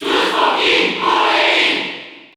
Category: Crowd cheers (SSBU) You cannot overwrite this file.
Lemmy_Cheer_French_NTSC_SSBU.ogg